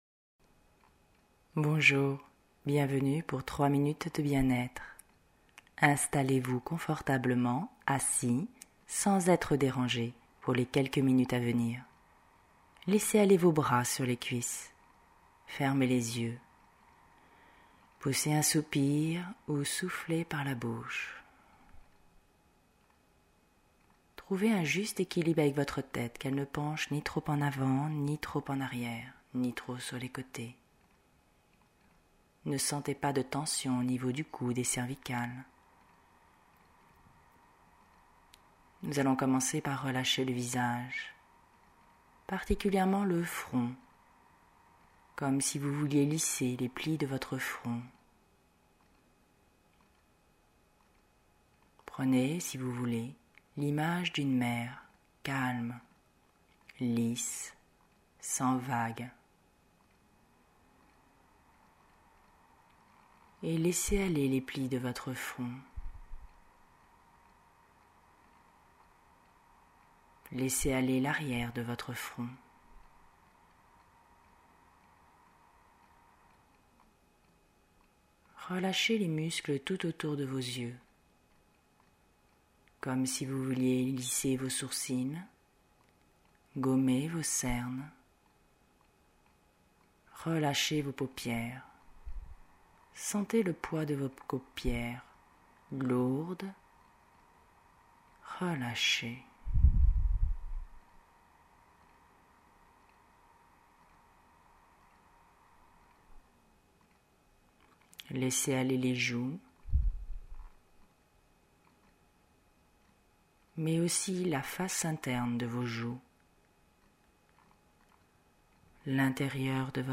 Genre : sophro
relaxation-visage.mp3